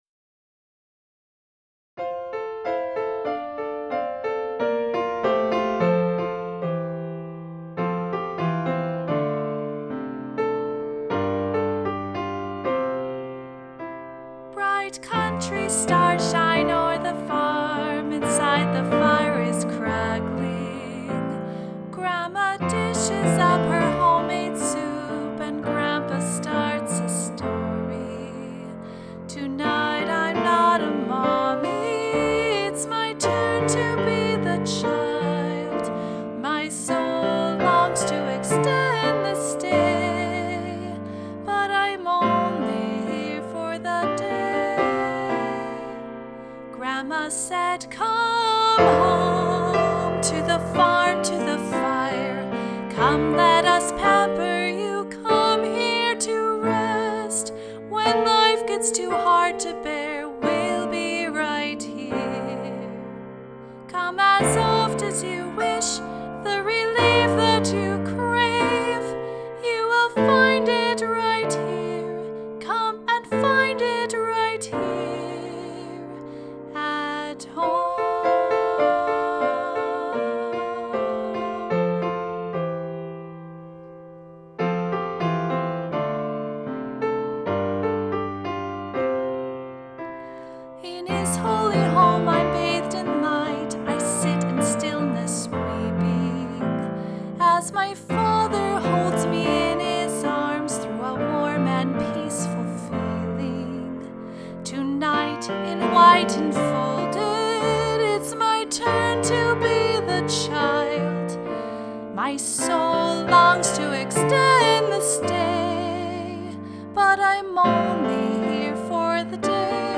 This is the first that I have attempted from start to finish on my own (meaning from the moment an idea came for the piece all the way to the final master copy).